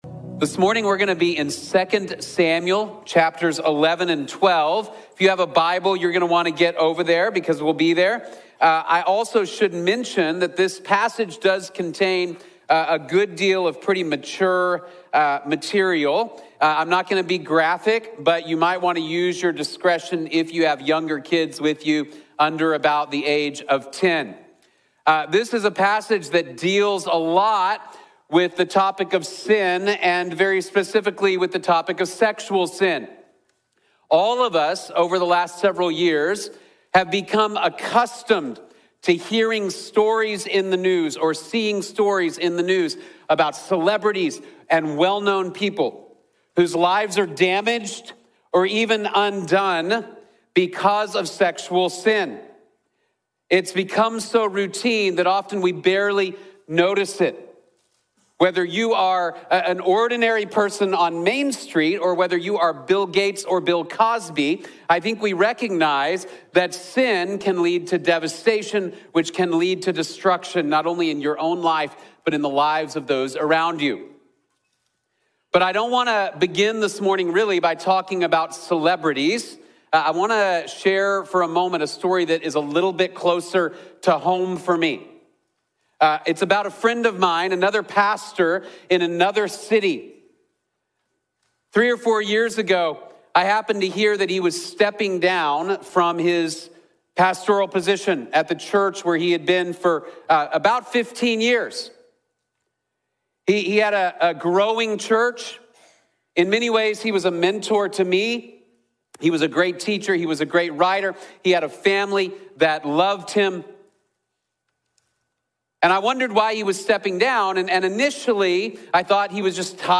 Resisting Sin’s Snares | Sermon | Grace Bible Church